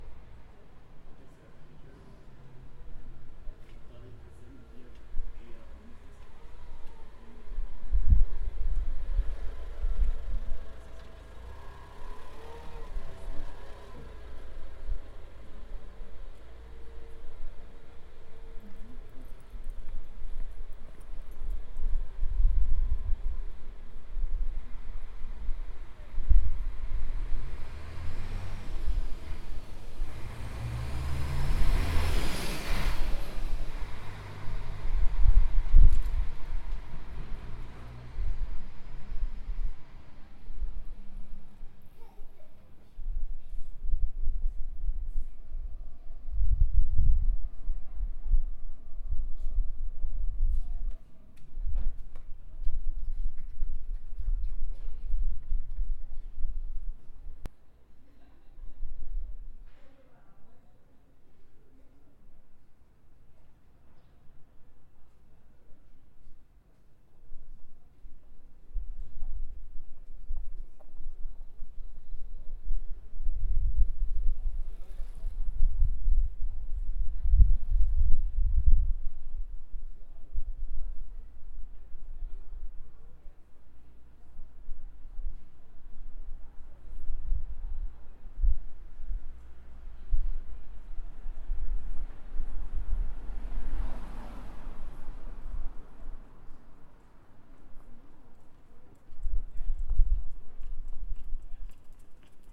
Noise is all around us
For this analysis, I´ve recorded the sound of 5 points in Jakomini Viertel, at 12am and 12pm of the same day.
Klosterwiesgasse | day